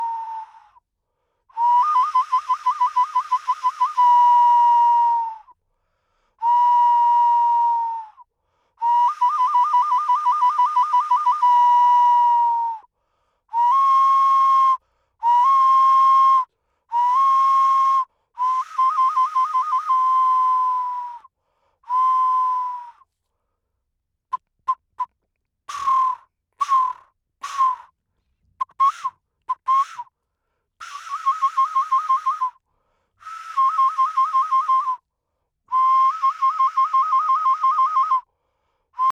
Audio 3. Ocarina de cerámica en forma de jugador de pelota, contexto ritual del Patio 1, Complejo Oeste del Grupo B (fig. 7d).